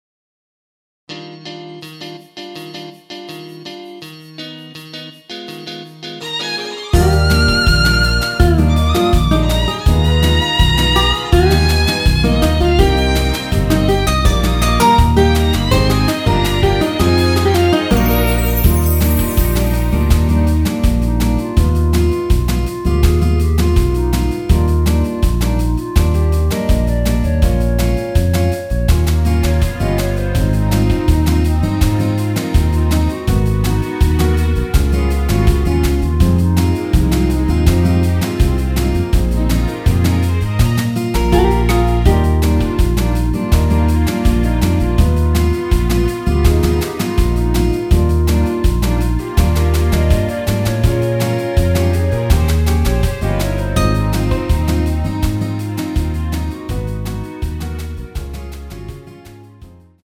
원키에서(+2)올린 멜로디 포함된 MR입니다.
Eb
앞부분30초, 뒷부분30초씩 편집해서 올려 드리고 있습니다.
중간에 음이 끈어지고 다시 나오는 이유는